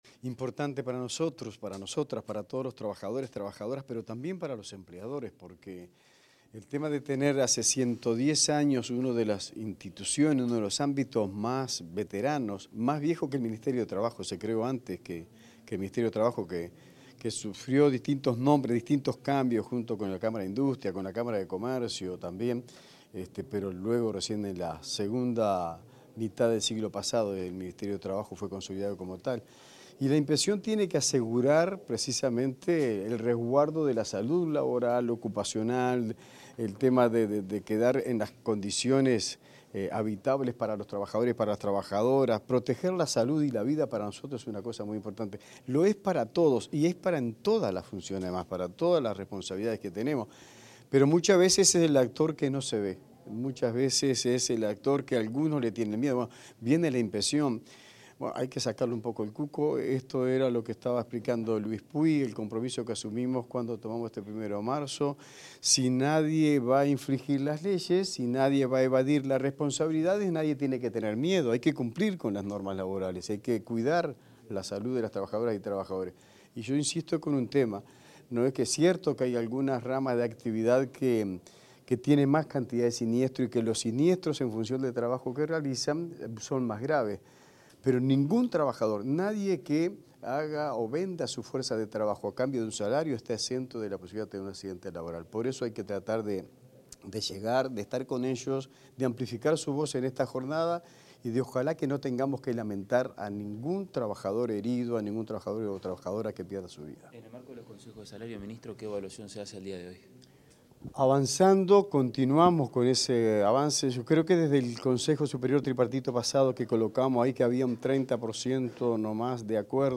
Declaraciones del ministro de Trabajo y Seguridad Social, Juan Castillo
Declaraciones del ministro de Trabajo y Seguridad Social, Juan Castillo 18/11/2025 Compartir Facebook X Copiar enlace WhatsApp LinkedIn En la conmemoración del 110° aniversario de la Inspección General del Trabajo y de la Seguridad Social (IGTSS), el ministro de Trabajo y Seguridad Social, Juan Castillo, brindó declaraciones a la prensa.